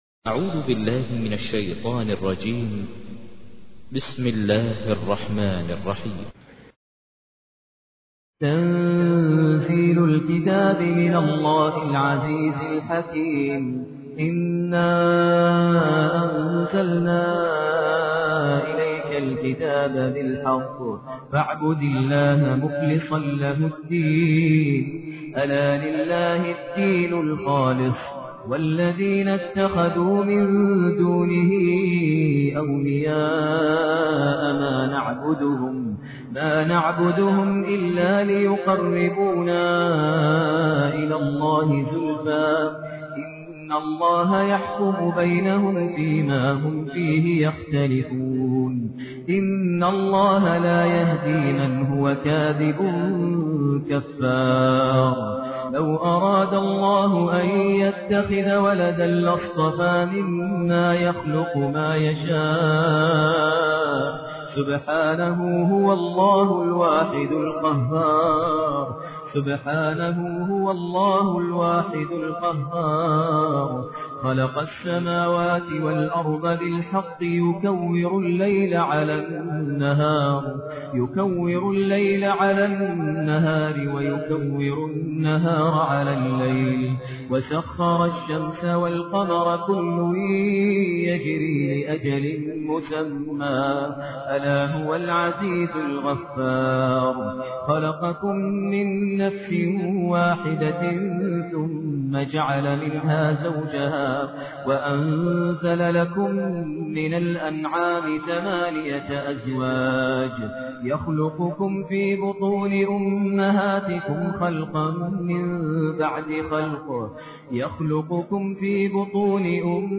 تحميل : 39. سورة الزمر / القارئ ماهر المعيقلي / القرآن الكريم / موقع يا حسين